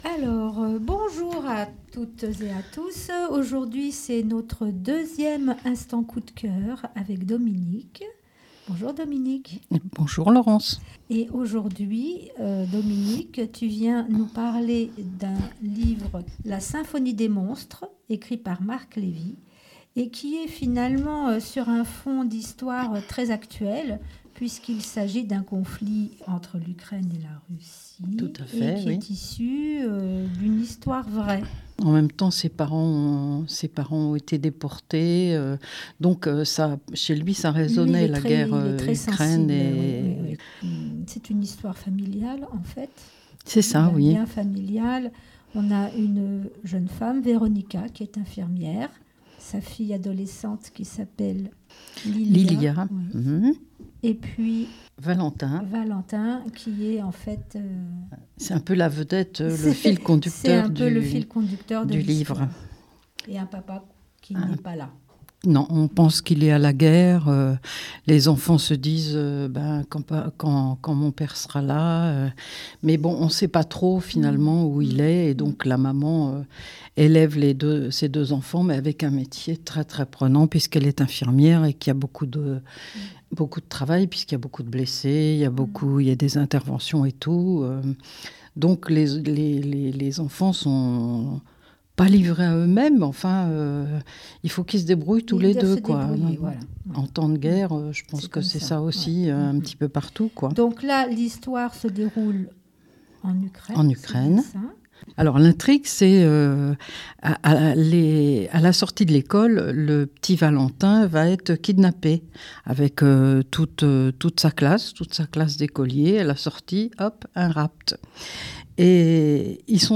nous résume le livre et partage avec nous quelques extraits !